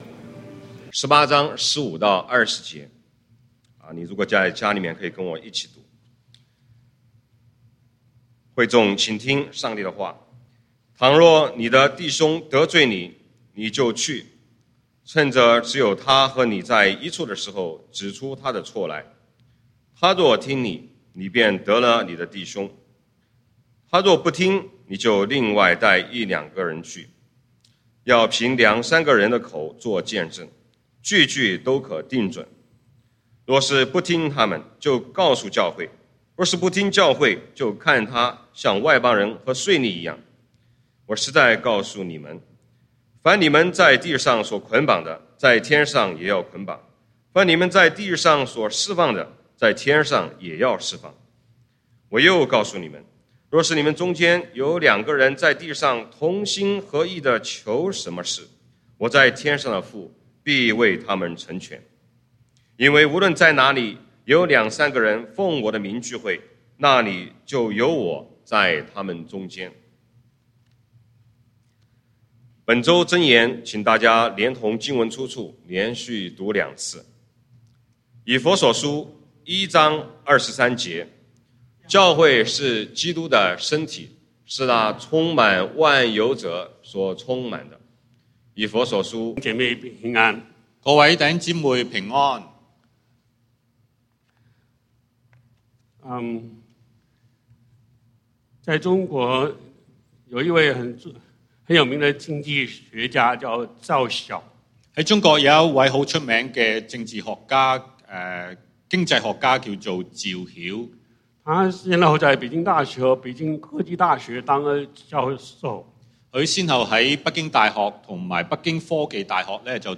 9/6/2020 講道經文：《馬太福音》Matthew 18:15-20 本週箴言：《以弗所書》Ephesians 1:23 「教會是基督的身體，是那充滿萬有者所充滿的。」